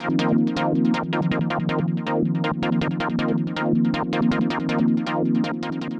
Ambient / Keys / SYNTH007_AMBNT_160_C_SC3.wav
1 channel